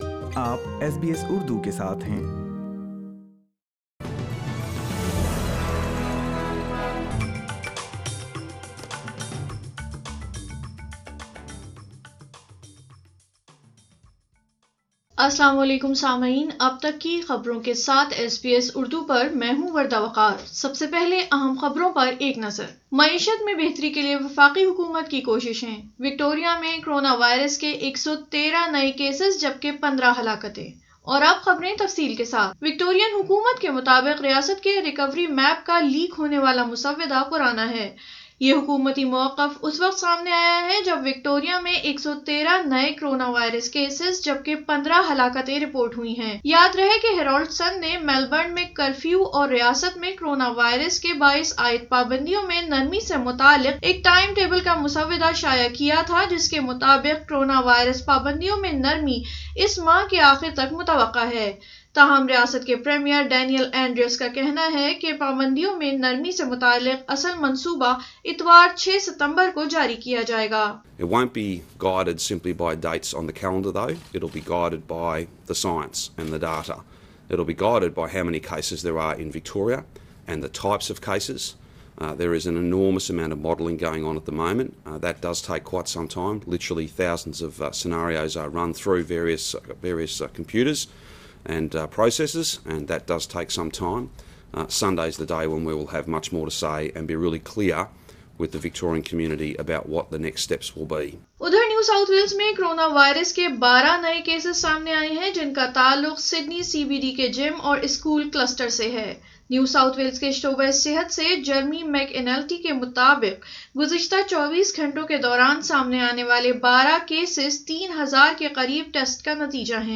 اردو خبریں 03 ستمبر 2020